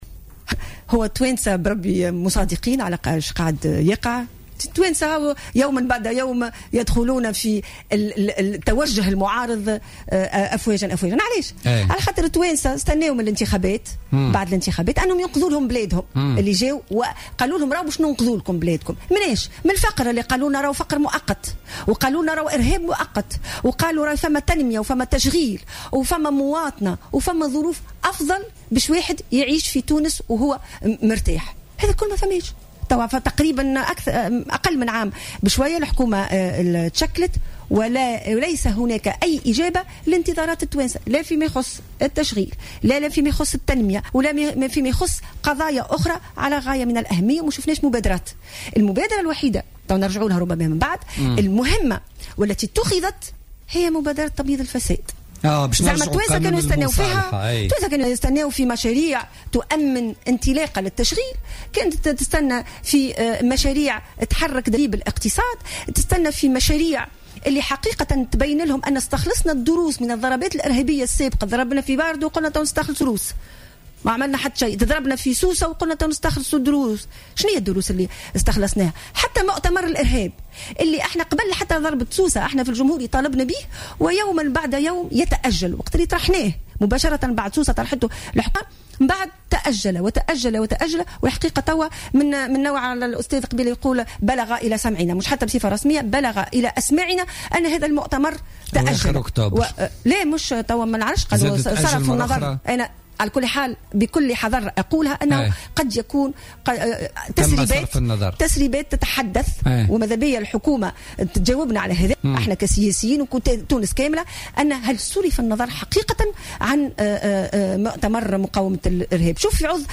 اعتبرت الأمينة العامة للحزب الجمهوري مية الجريبي في تصريح للجوهرة أف أم في برنامج بوليتكا لليوم الخميس 01 أكتوبر 2015 أن المبادرة الوحيدة التي تم اتخاذها في هذه الحكومة هي قانون المصالحة وهو مشروع لتبييض الفساد.